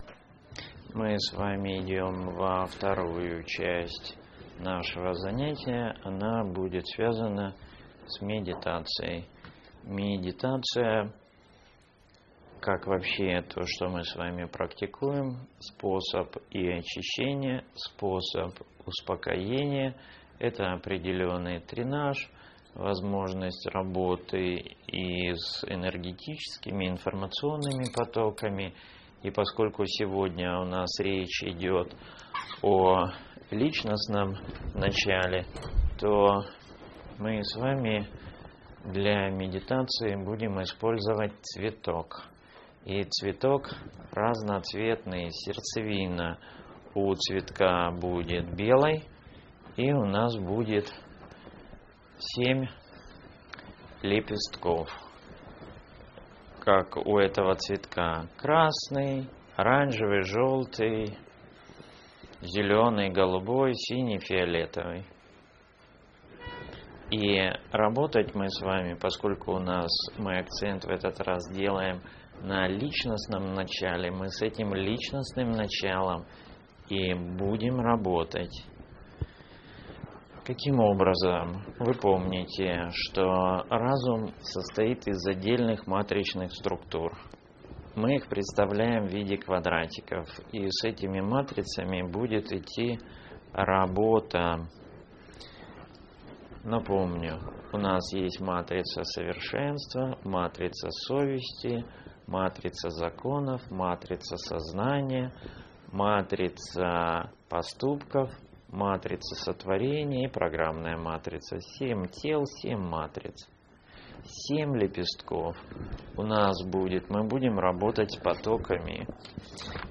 Лекции Семинар
Цветовая медитация